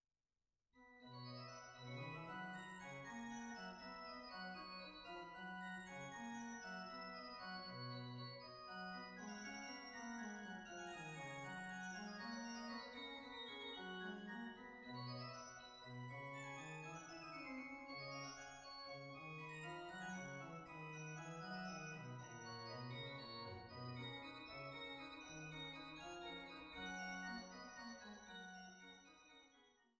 Schramm-Orgel der Stadtkirche St. Otto zu Wechselburg
c-Moll: Allegro un poco andante - Largo - Allegramente